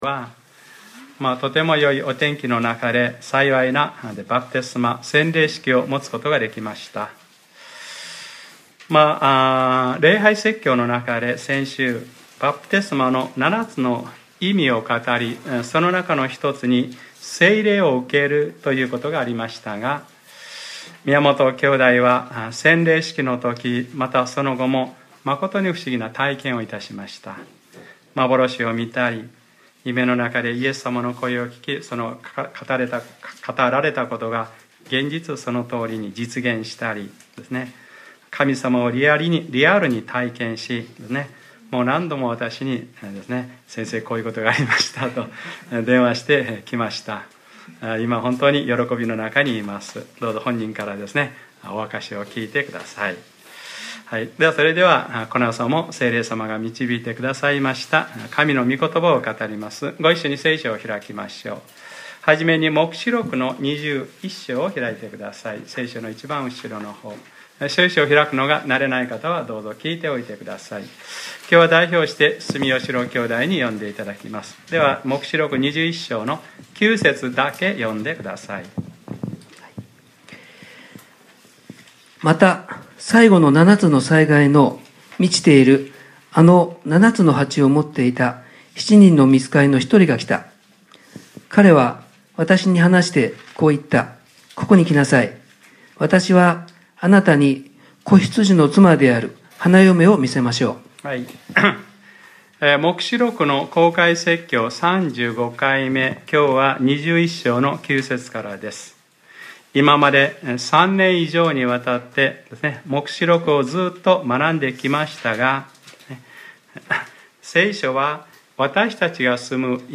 2014年10月26日（日）礼拝説教 『黙示録ｰ３５：聖なる都 天のエルサレム』